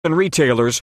Many of the most common little words of English are usually pronounced with a weak, colourless vowel ‘schwa’, /ə/.